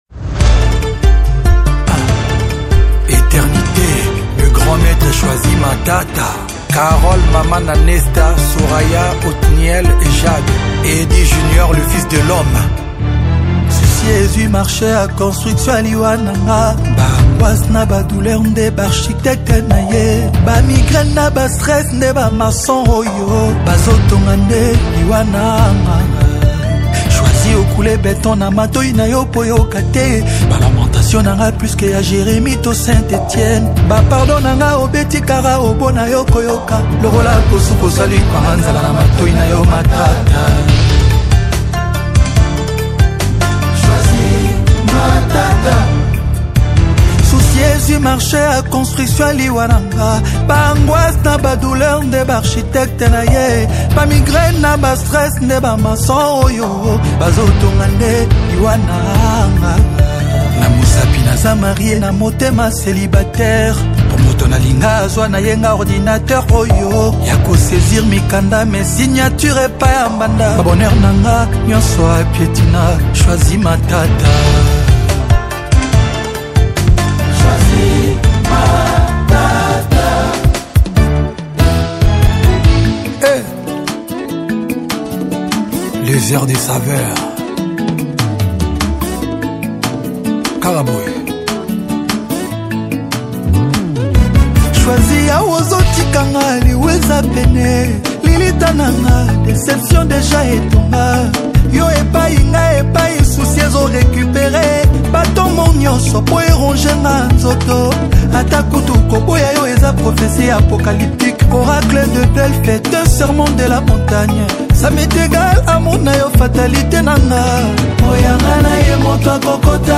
| Rumba